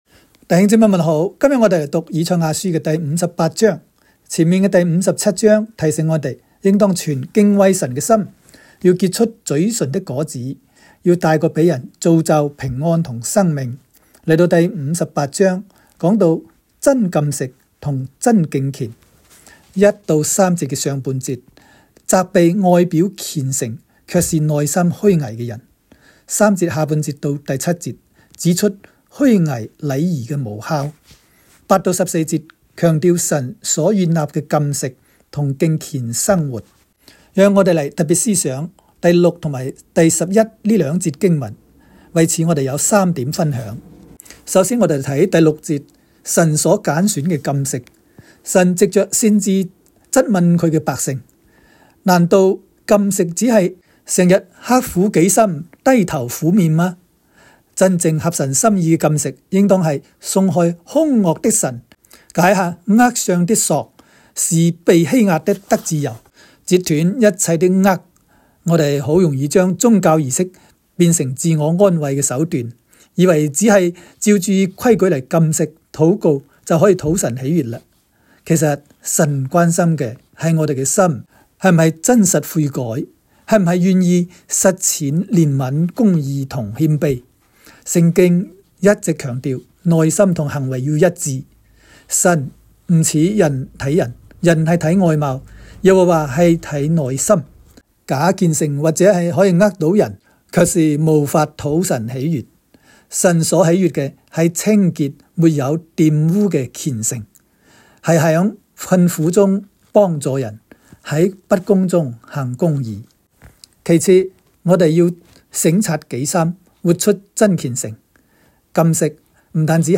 赛58（讲解-粤）.m4a